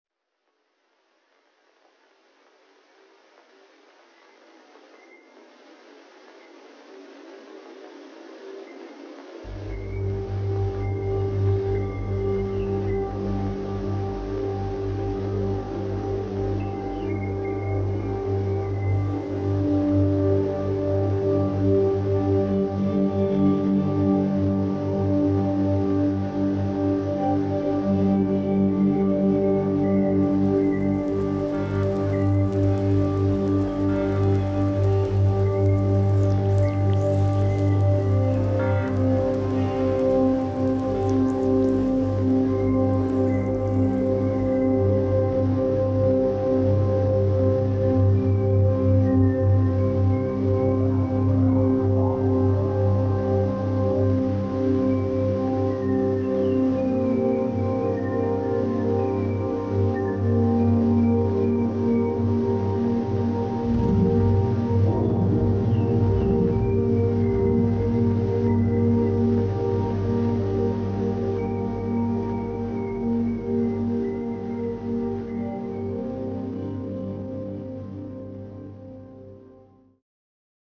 3D mekansal ses ile zenginleştirilen